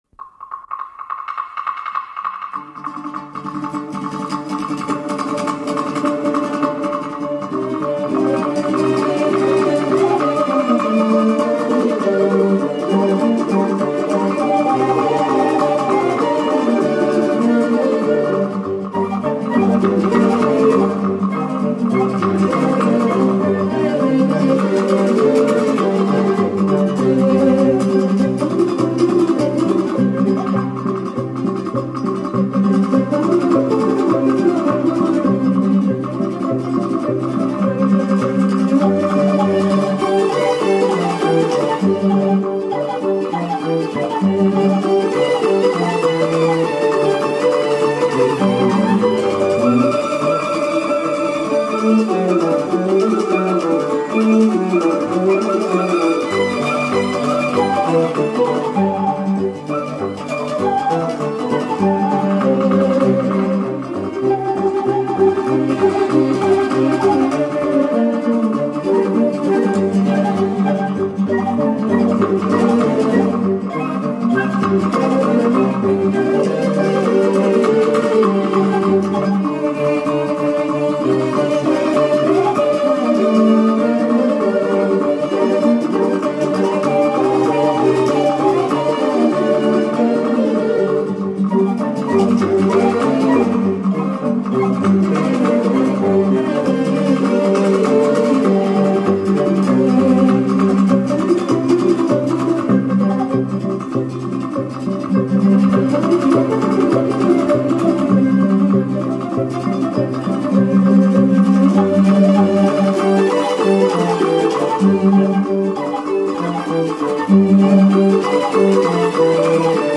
отличается душевностью и глубиной
наполнен эмоциями